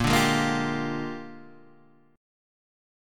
A#13 chord